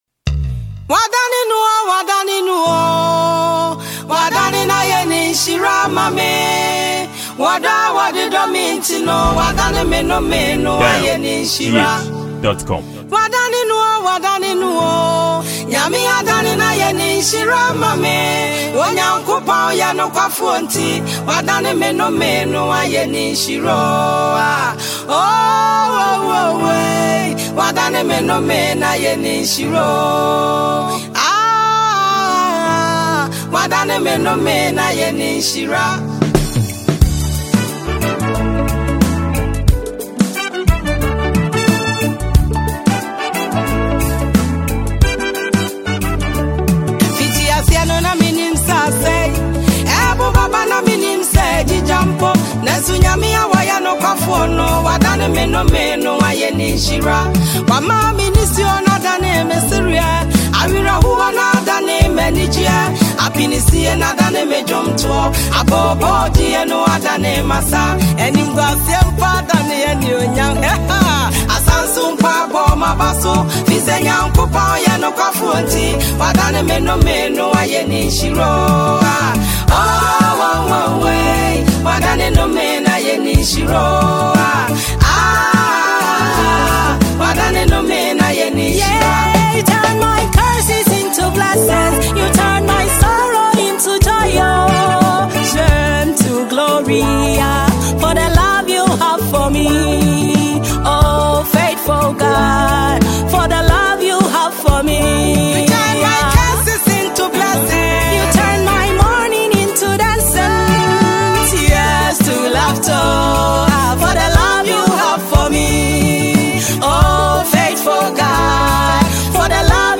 Ghanaian gospel singer and songwriter
gospel tune